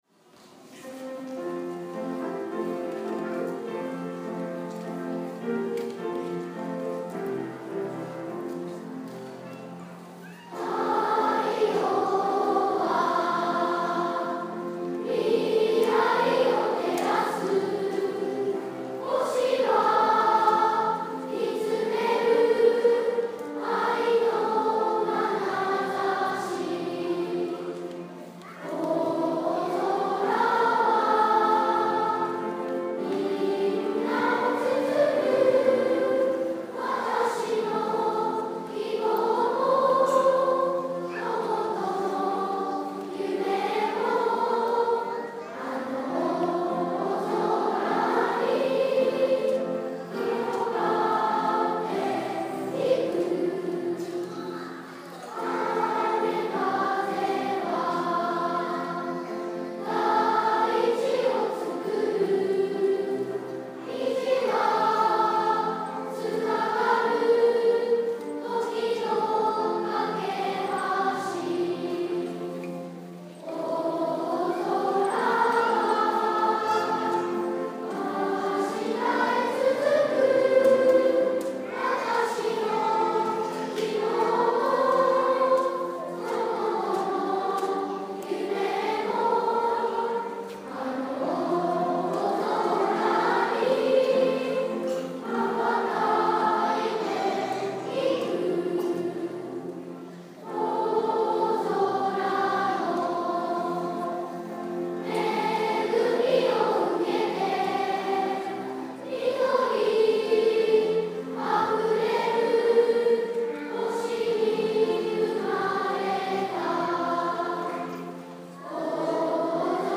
2017年6月3日（土）　ふれあい科　12ｔｈ　大空創立記念コンサート
力強いスネアドラムの音が響き、大空小学校校歌が流れ、校旗が入場します。